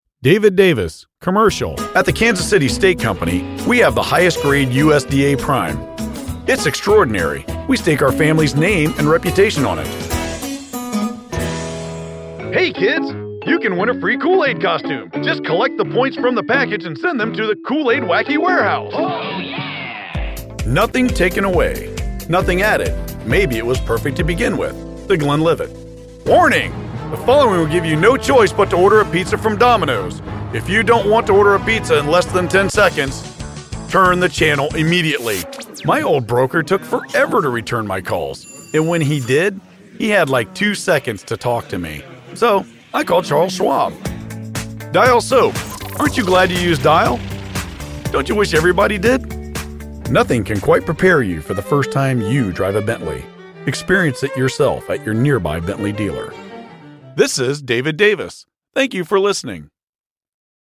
From calm and soothing to outrageous and zany, I can tailor my voice and delivery to embrace a wide range of scenarios.
That is why I will provide a finished product that you can be proud of using my personal, professional studio.